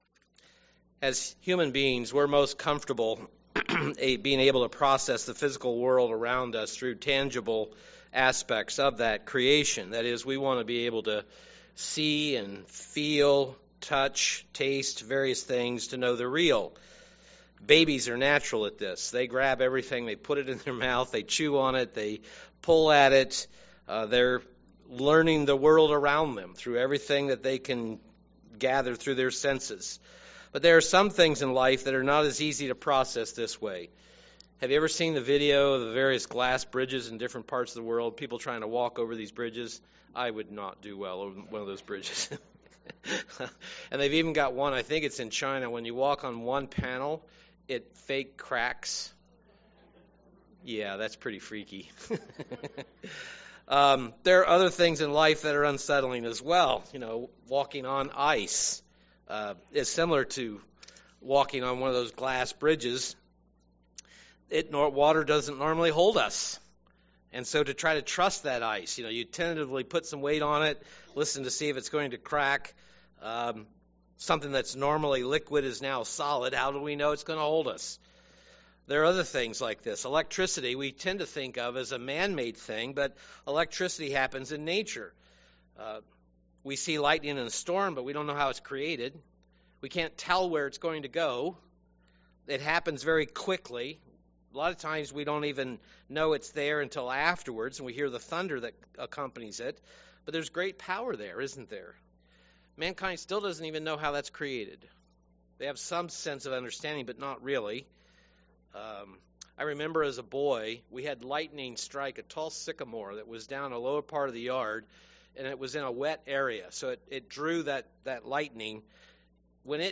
UCG Sermon Pentecost Holy Spirit helper power of God Studying the bible?